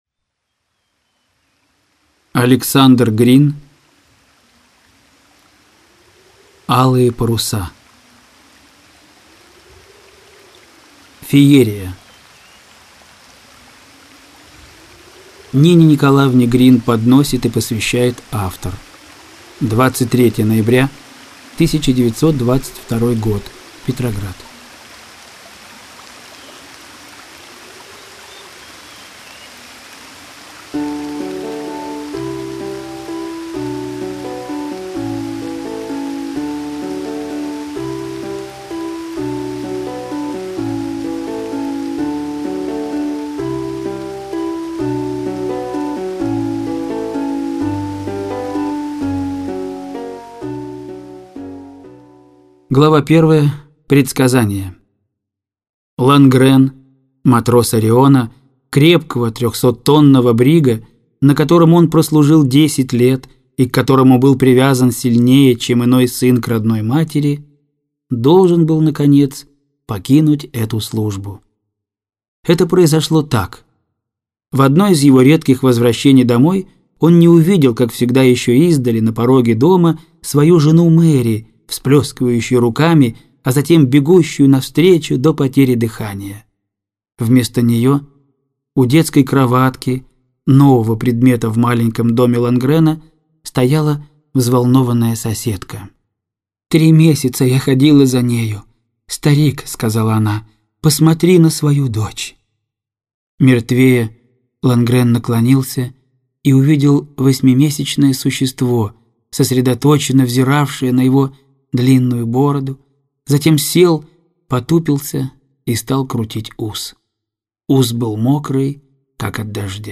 Аудиокнига Алые паруса - купить, скачать и слушать онлайн | КнигоПоиск